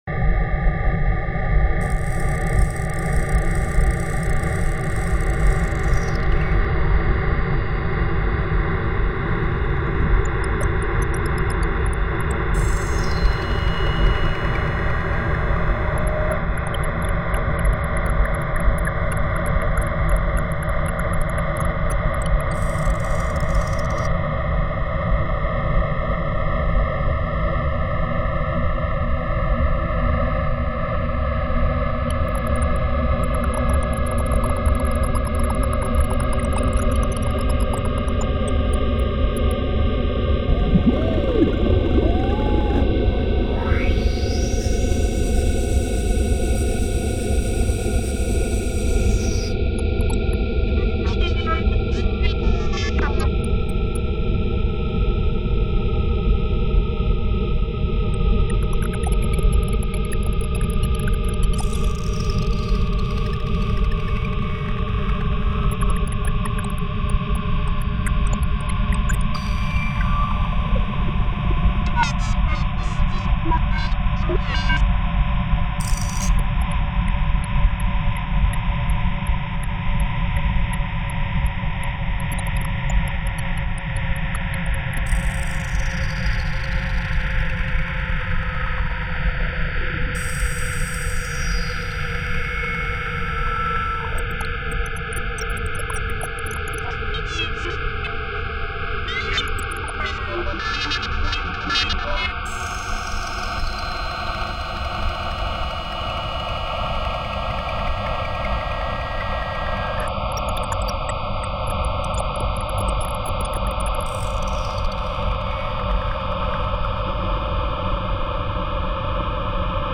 Draft version of Plantl-ife inspired sound design